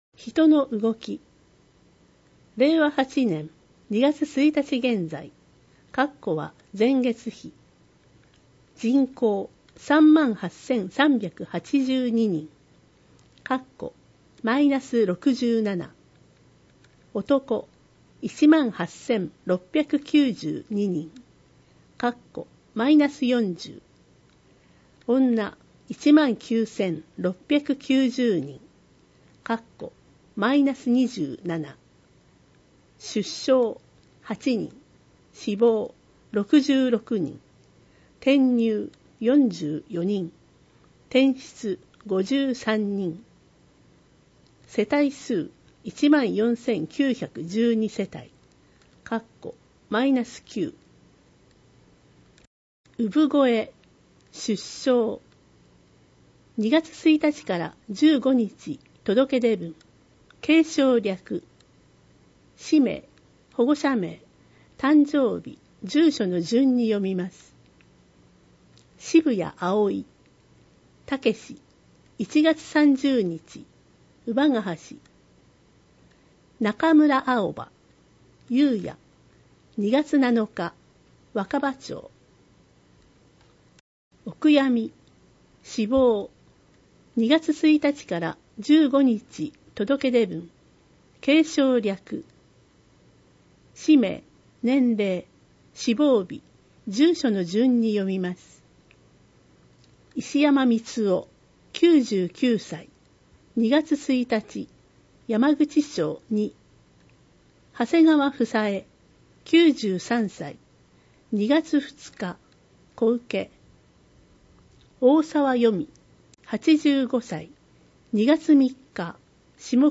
市では、視覚に障がいのある方向けに、ボランティア団体「うぐいす会」の皆さんのご協力により、広報あがのを音声訳したCDを作成し、希望する方に配付しています。